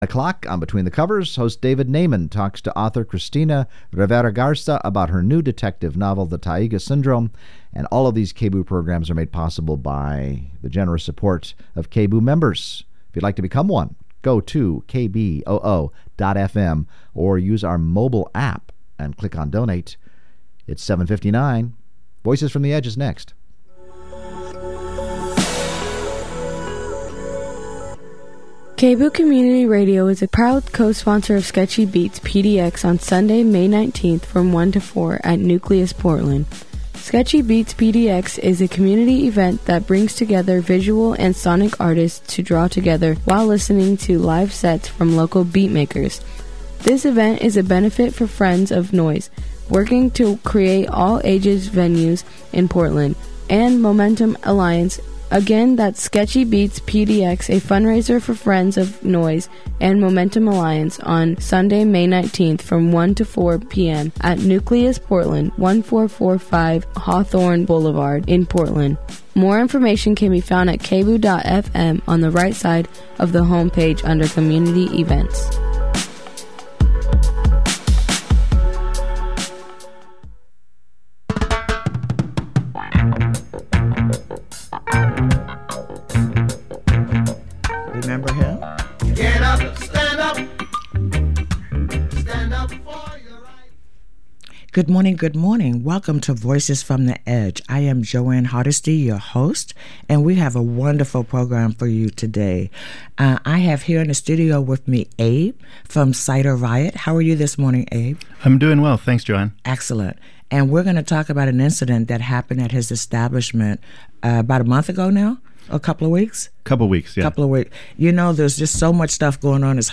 Progressive talk radio from a grassroots perspective
With an hour to invest, the call-in format engages listeners in meaningful conversations about crucial issues like racial disparity, government accountability, environmental justice and politics on local, state and national levels.